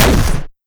Sci-Fi Effects
weapon_shotgun_006.wav